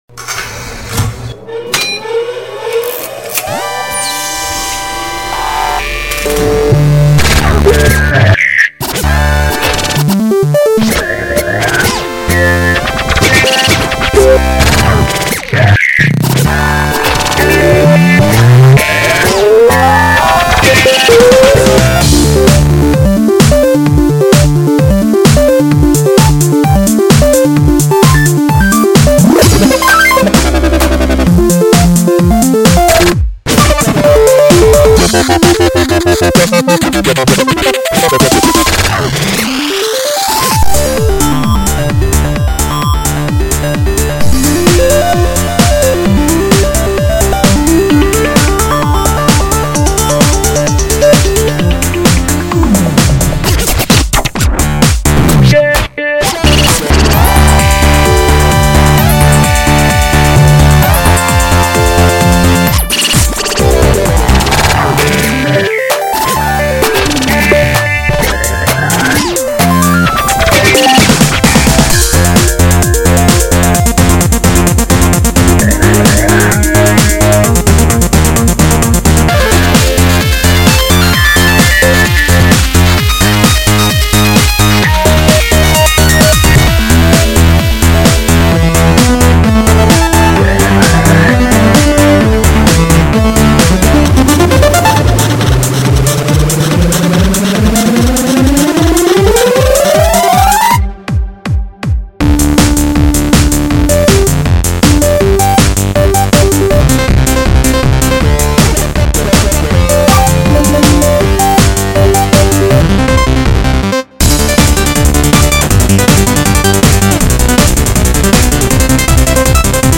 genre:chiptune